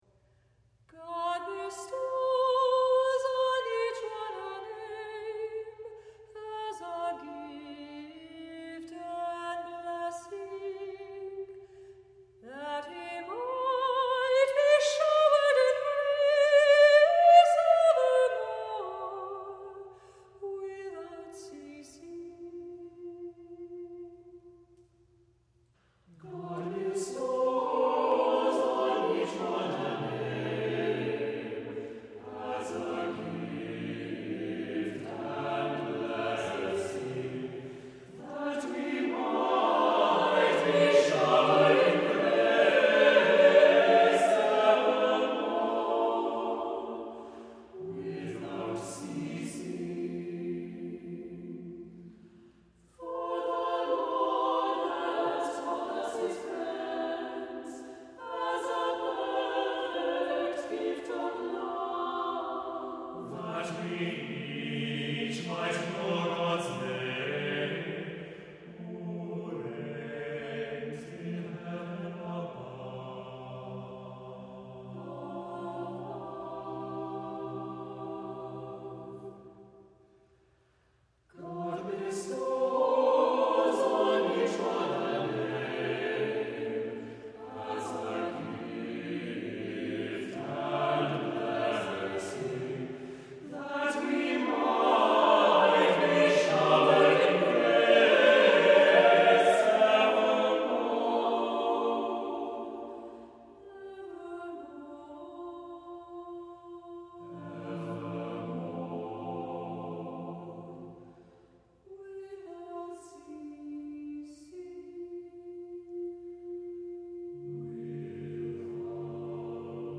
Voicing: Soprano Solo and SAATB a cappella